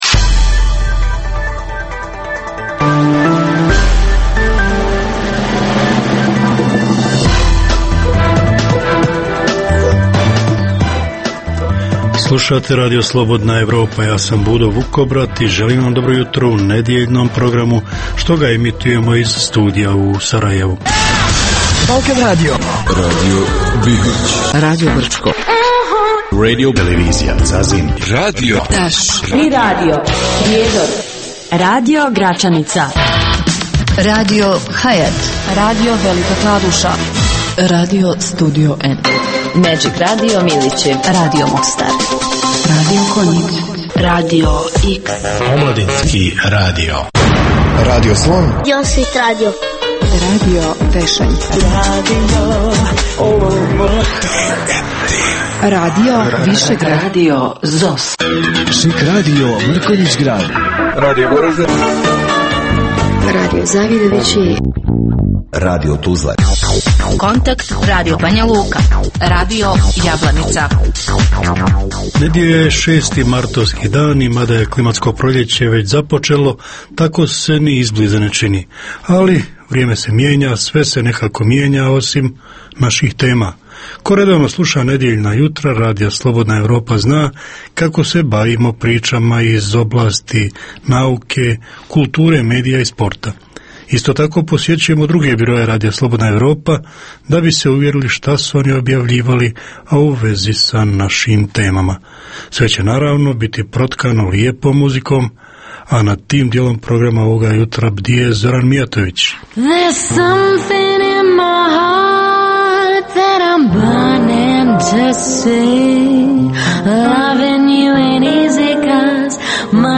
Jutarnji program namijenjen slušaocima u Bosni i Hercegovini. Kao i obično, uz vijesti i muziku, poslušajte pregled novosti iz nauke i tehnike, te čujte šta su nam pripremili novinari RSE iz Zagreba i Beograda.